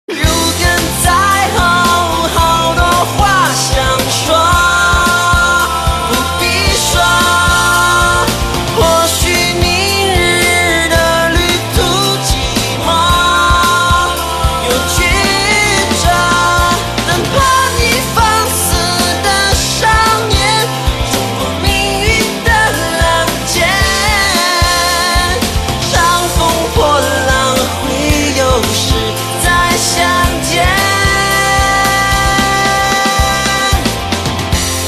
M4R铃声, MP3铃声, 华语歌曲 100 首发日期：2018-05-14 14:07 星期一